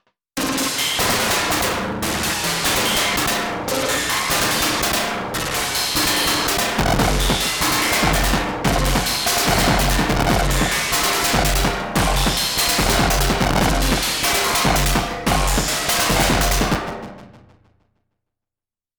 … two times Digitone from me, to be paired with Syntakt …